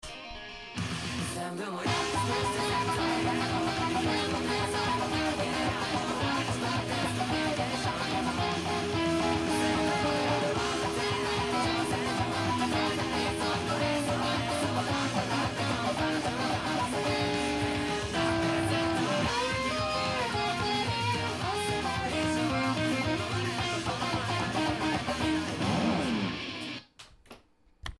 曲のほうは、超高速で疾走感抜群のナンバーです！
音源にあわせて譜面通りギターで弾いてみました
主にコードトーンを中心とした２音でギターパートを作成しています。
エレキギターでチャカチャカさせながら弾く感じになります。